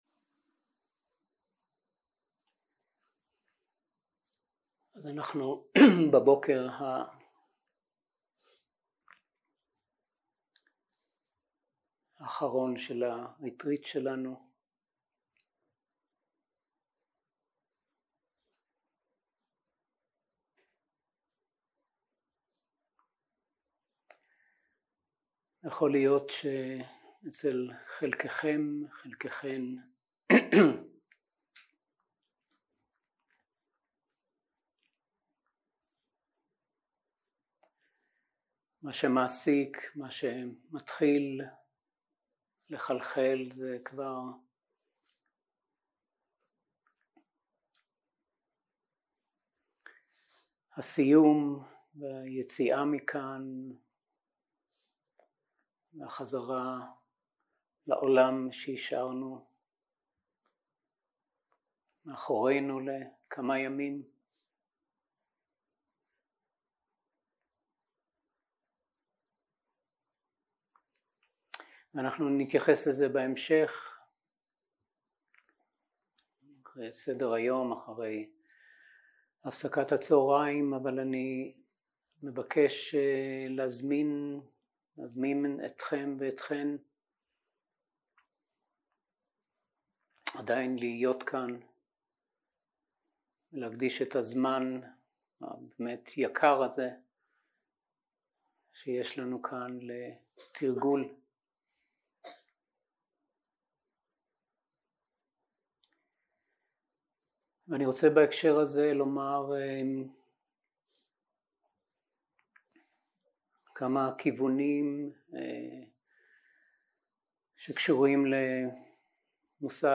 יום 4 - בוקר - שיחת דהרמה וישיבה שקטה - הקלטה 8
סוג ההקלטה: שיחות דהרמה